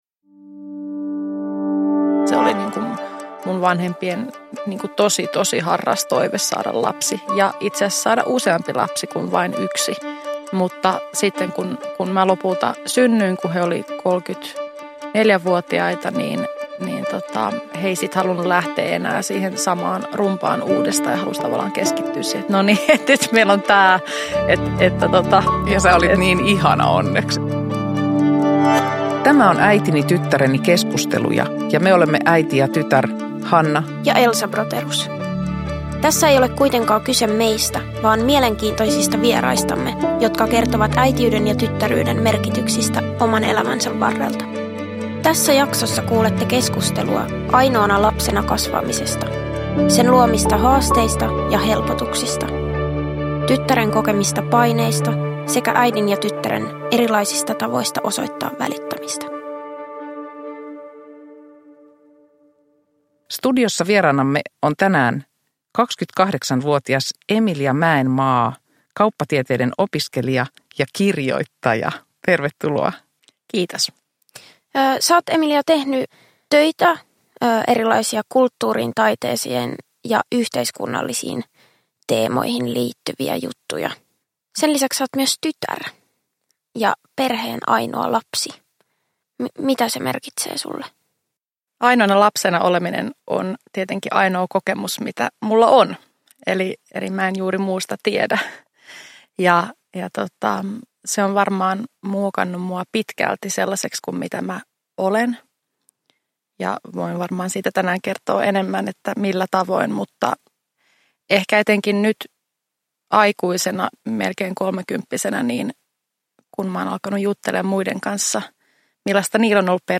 Äitini, tyttäreni -keskusteluja osa 5 – Ljudbok
Avointa ja koskettavaa keskustelua äitinä ja tyttärenä olemisesta